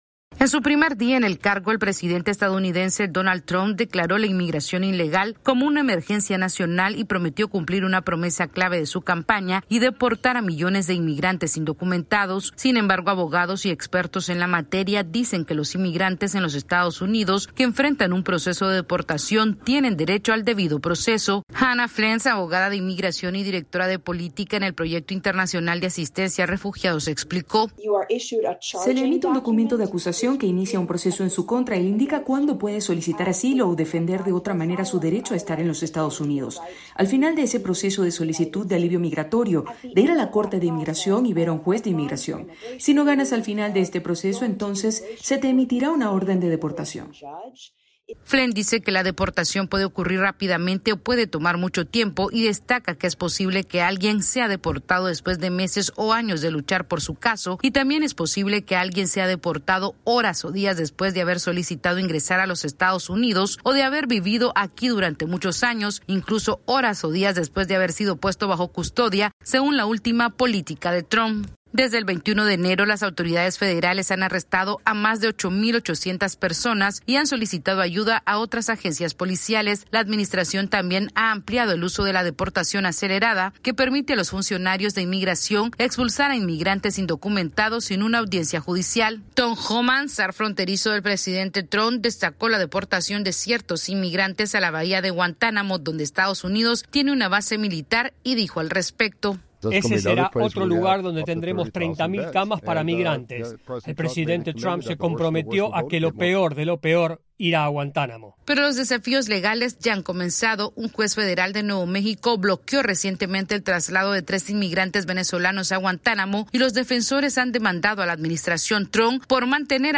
AudioNoticias
La Administración del presidente Donald Trump está avanzando rápidamente con sus aviones de deportación masiva al enviar a algunos migrantes a la Bahía de Guantánamo en Cuba y la rápida deportación de otras personas. Esta es una actualización de nuestra Sala de Redacción.